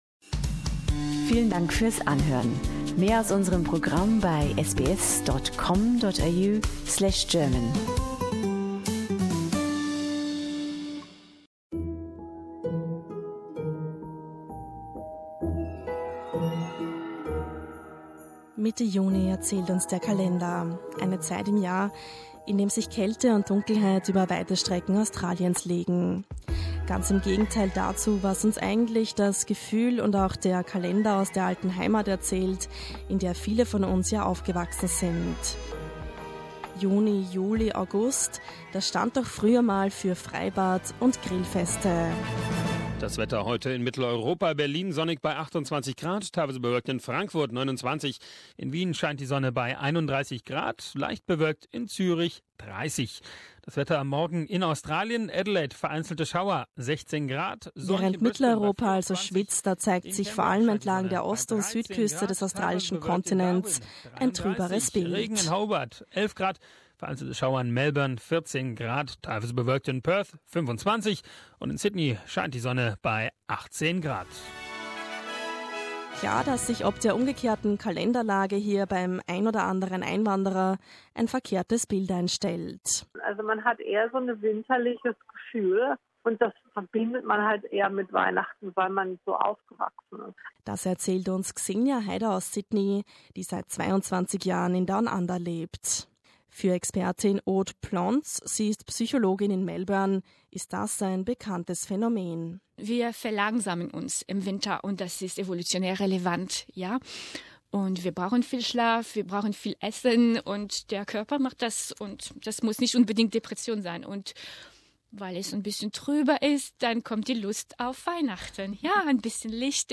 Statt Freibad und Grillfeste wie in Europa heißt es zwischen Juni und August in vielen Teilen Australiens Kälte und Dunkelheit. Doch diese Reportage zeigt, dass die deutschsprachige Gemeinde im Winter noch enger zusammenrückt und Veranstaltungen organisiert, die das Herz erwärmen können.